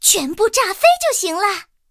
萤火虫-拉德利开火语音2.OGG